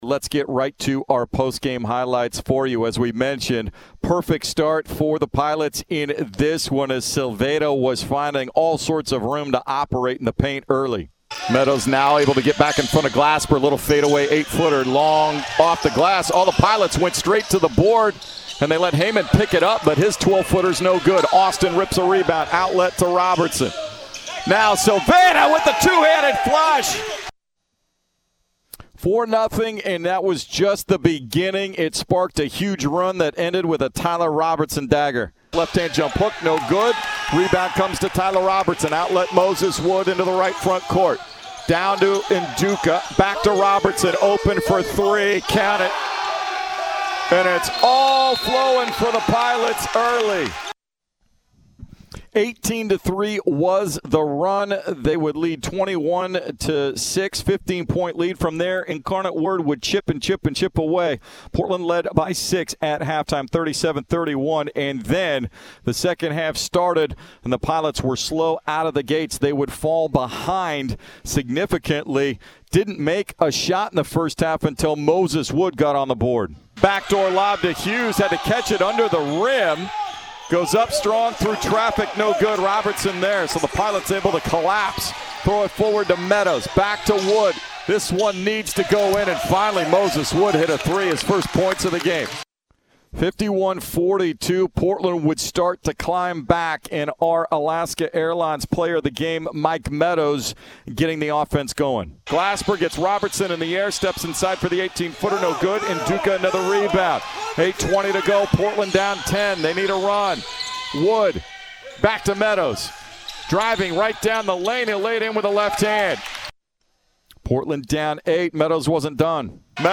Men's Basketball Radio Highlights vs. Incarnate Word
November 28, 2021 Radio highlights from Portland's 77-68 road victory over Incarnate Word.